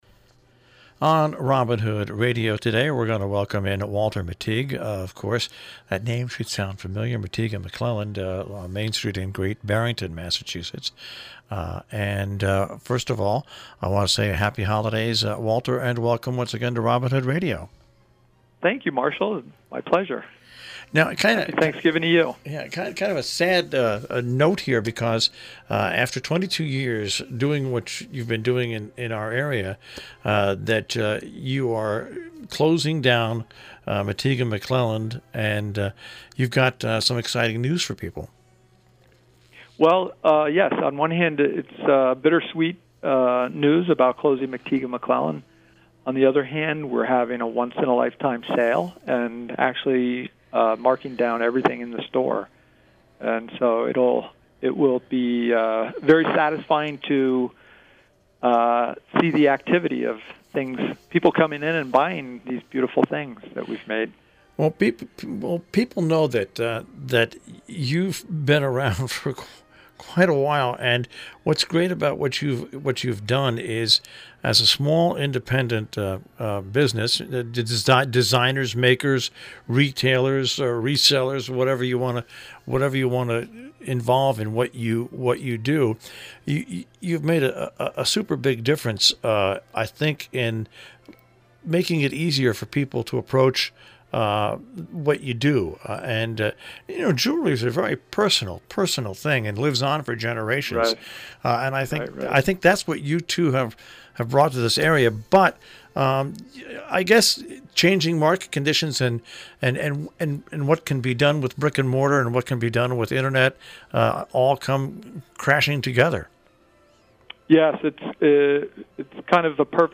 ROBIN HOOD RADIO INTERVIEWS